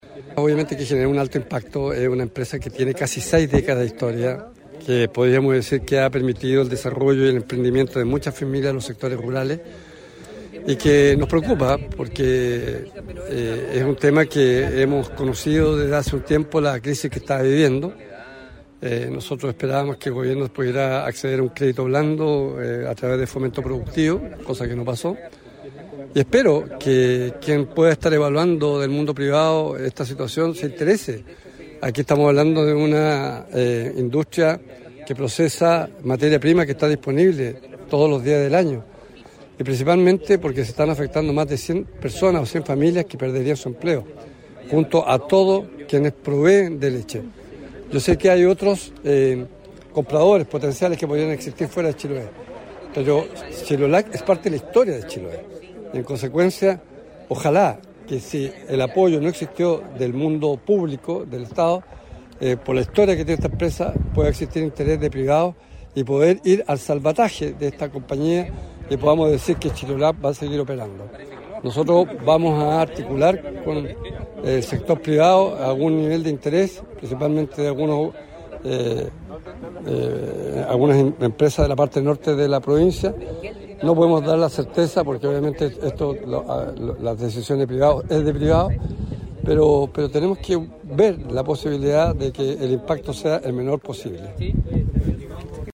El Gobernador regional Alejandro Santana se refirió al cierre de la planta Chilolac, calificándolo de un alto impacto social y productivo, por lo que llamó a los privados a interesarse en esta empresa.